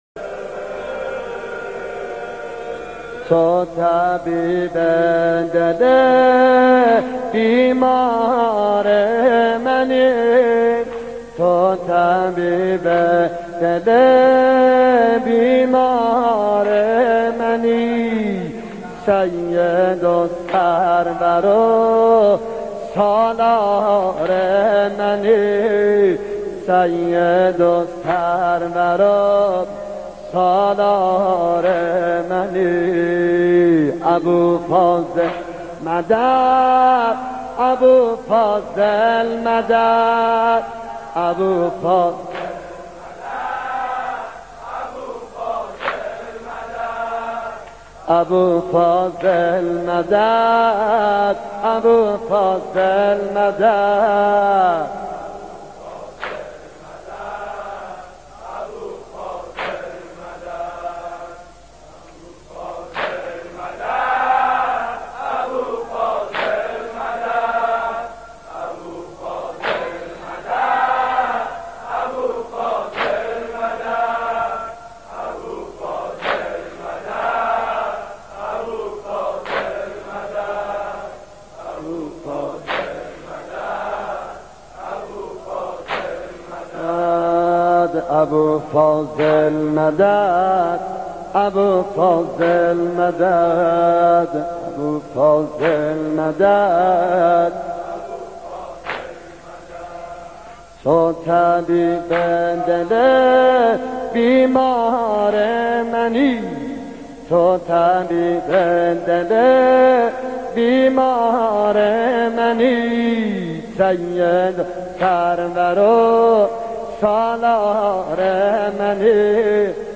مداحی قدیمی